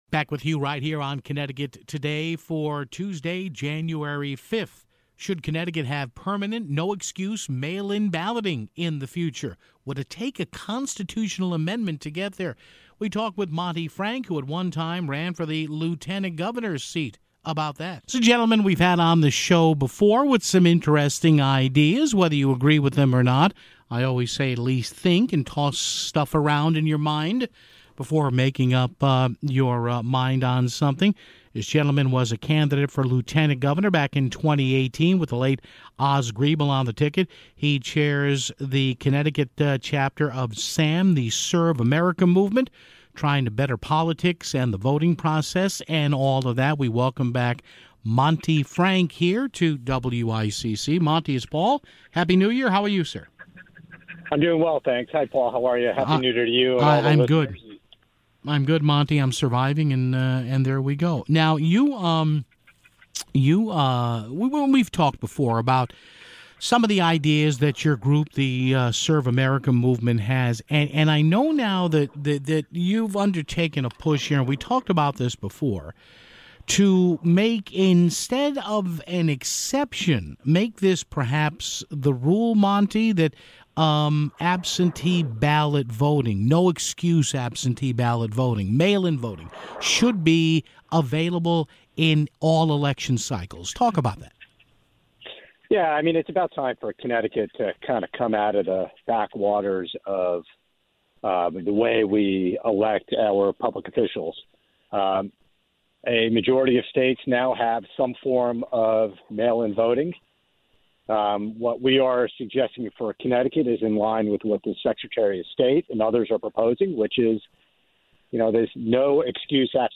call in to talk about the idea making mail in ballots permanent in the state of Connecticut (0:18)